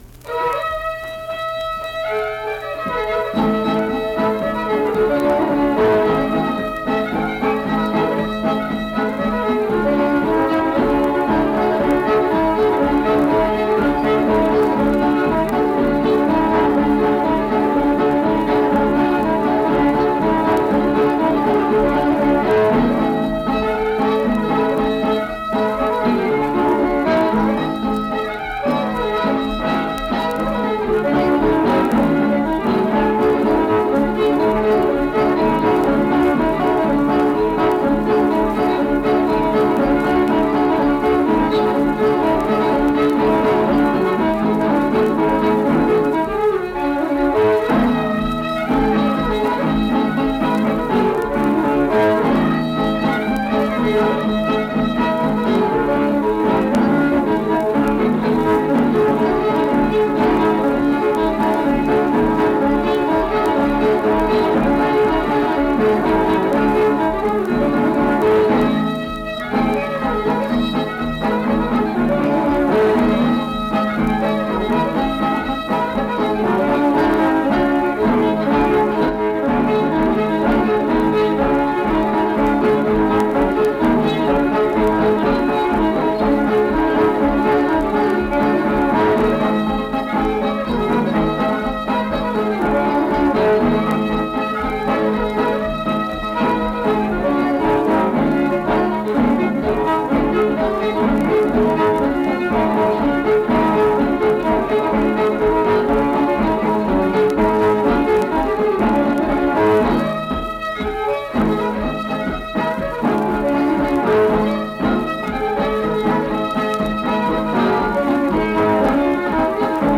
Accompanied guitar and unaccompanied fiddle music performance
Instrumental Music
Guitar, Fiddle
Mill Point (W. Va.), Pocahontas County (W. Va.)